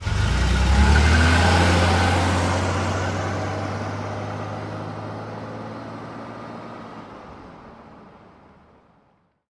pullaway_out1.ogg